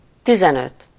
Ääntäminen
Ääntäminen France Tuntematon aksentti: IPA: /kɛ̃z/ Haettu sana löytyi näillä lähdekielillä: ranska Käännös Ääninäyte Substantiivit 1. tizenöt Adjektiivit 2. tizenöt Suku: m .